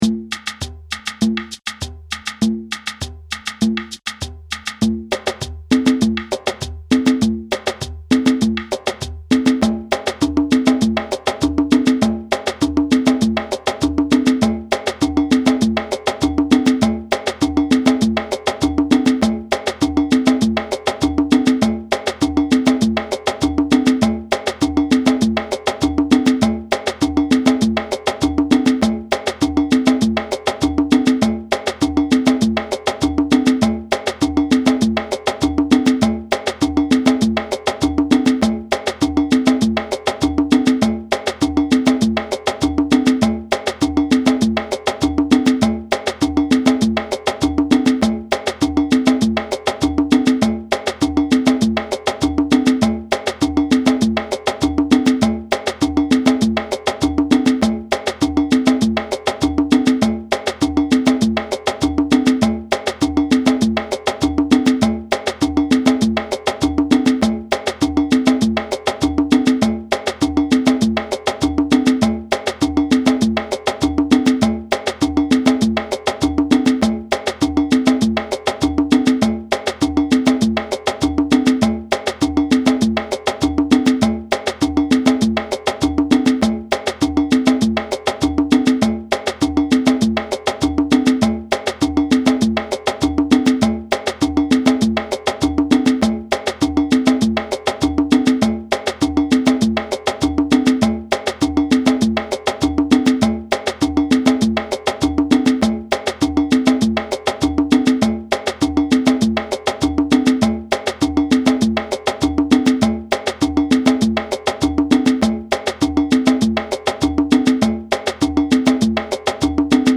A drum circle favorite, this electric rhythm incorporates West African and Brazilian rhythm concepts (in 4/4 time).
Full Ensemble
All parts played together with small percussion
W.-African-Samba-fast-Full-hh.mp3